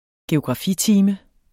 Udtale [ geogʁɑˈfi- ]